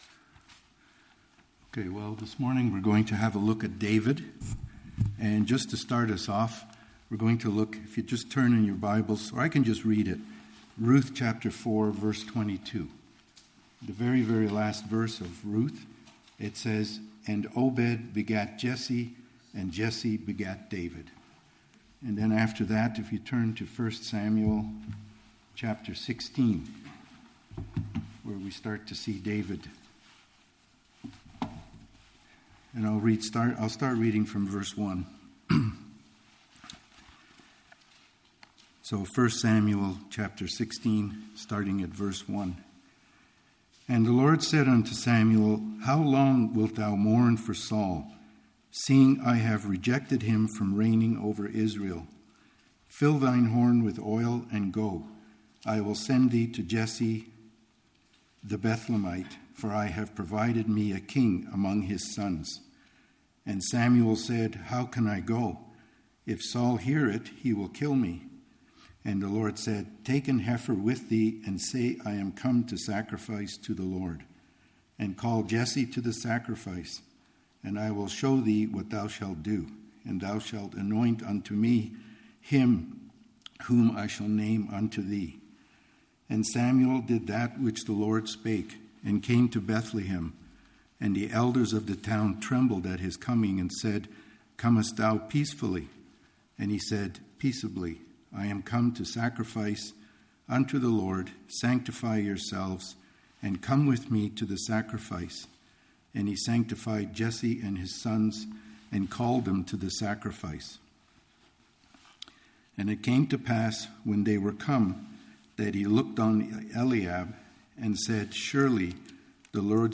1 Sam 16 Service Type: Mid week Beginning a new series on the life of King David.